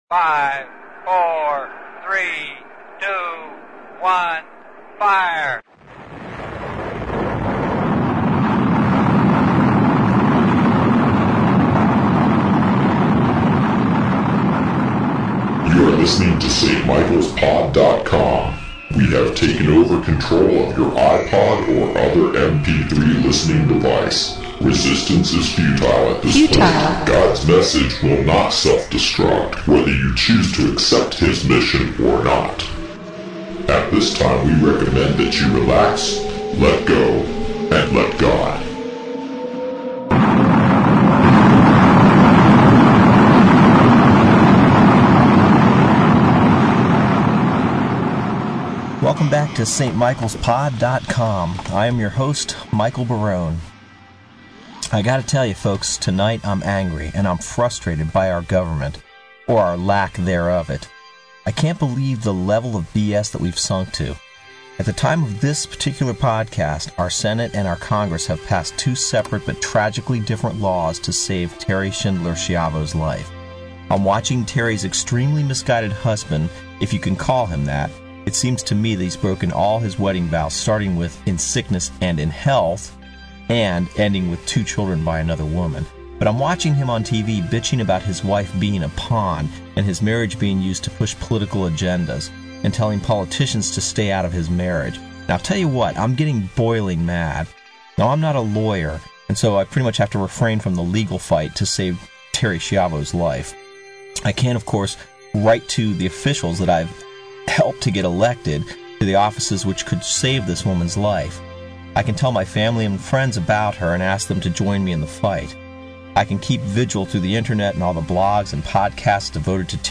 Some will be very poorly recorded, that is to say the quality was not all that great but they will be here anyway to give you some idea of the depth and changes for the span of years we played.